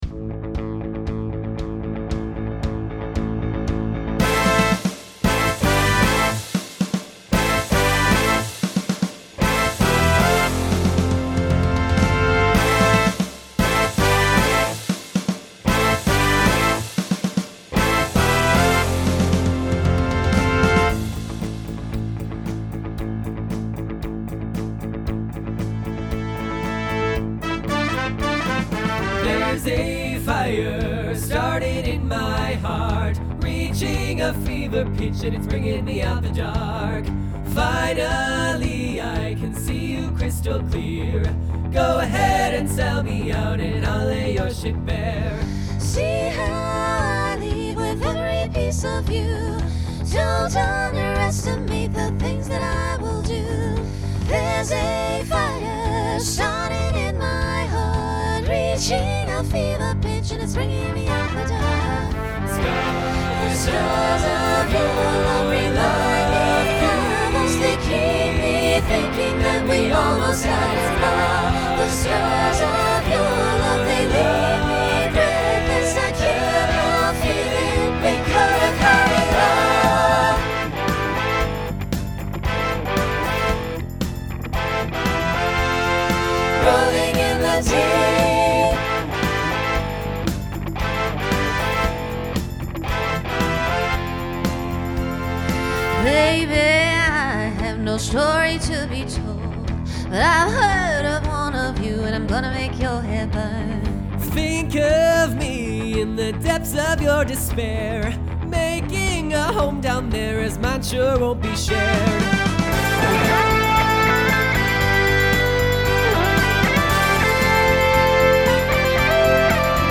Transition Voicing SATB